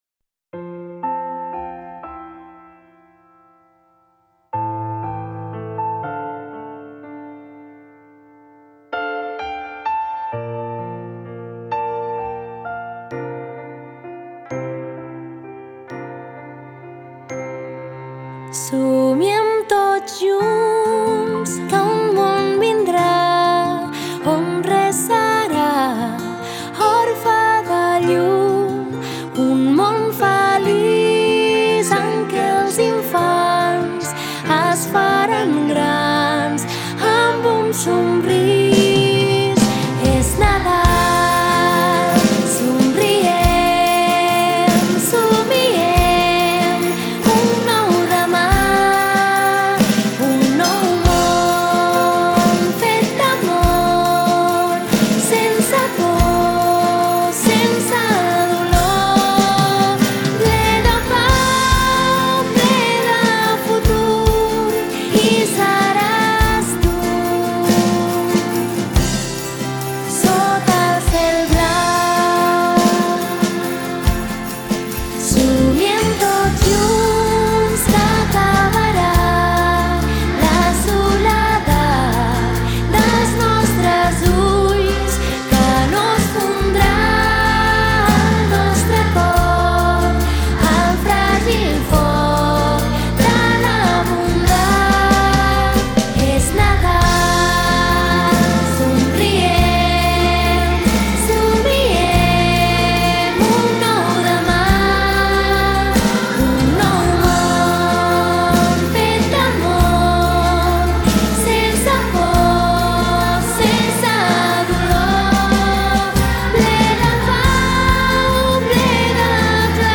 Cançó original amb veu: